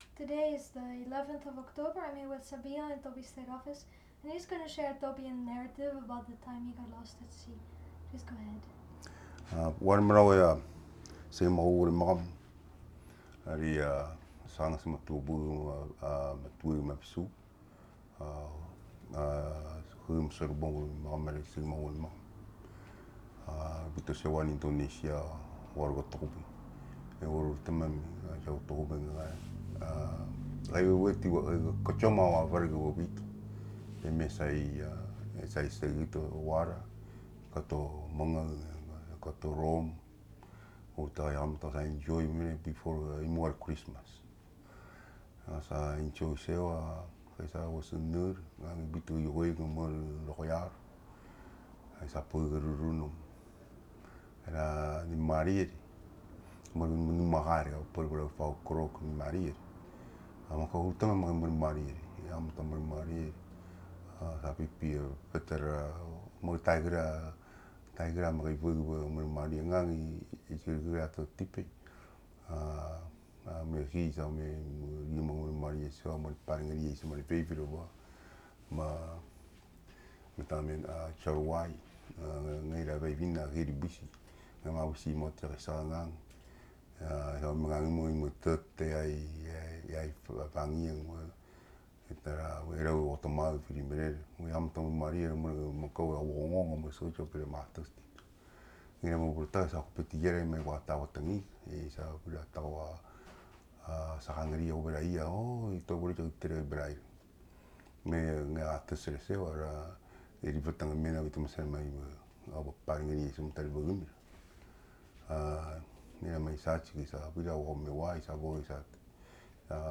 digital wav file recorded at 44.1 kHz/16 bit on Zoom H2N
Echang, Koror, Palau